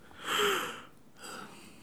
baillement_01.wav